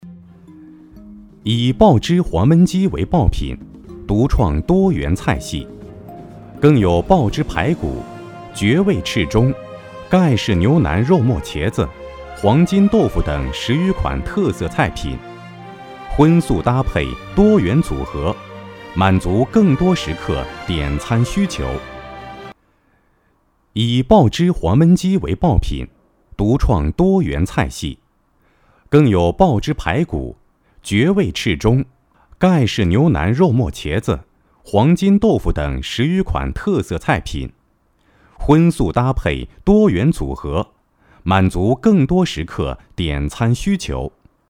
舌尖-男32-黄焖鸡.mp3